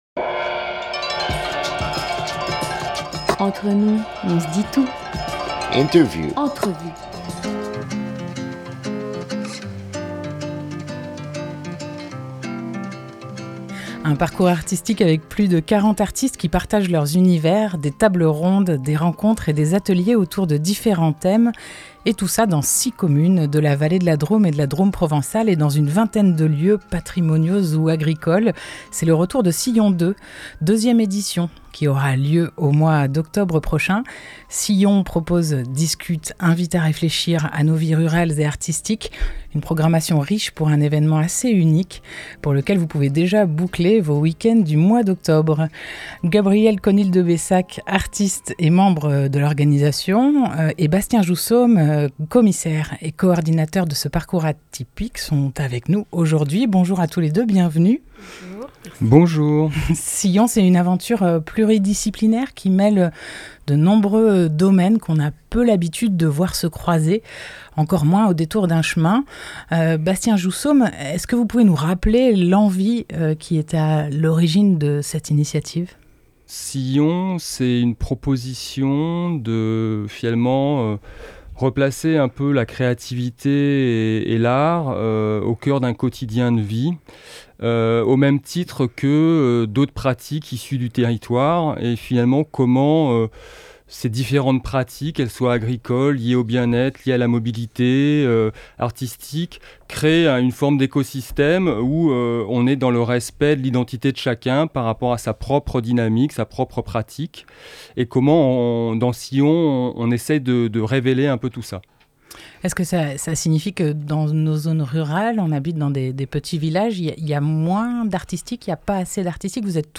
19 juillet 2021 7:00 | Interview